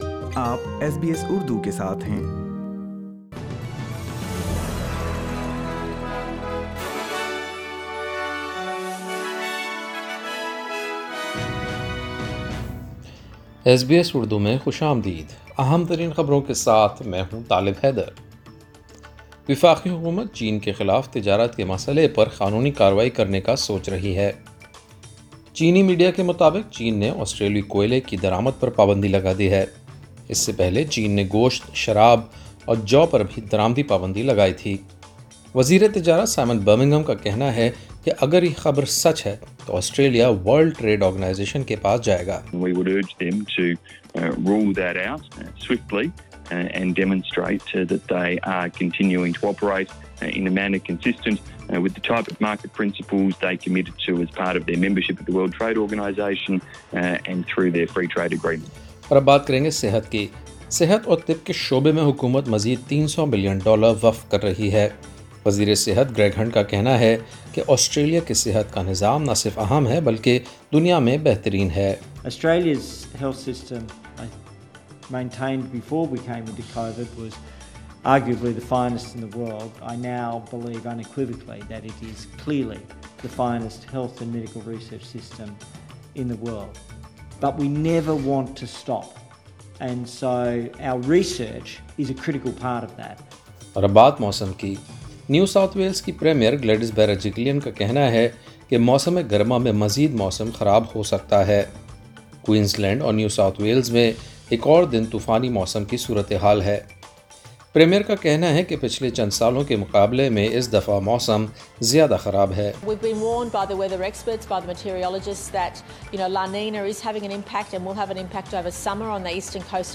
ایس بی ایس اردو خبریں 15 دسمبر 2020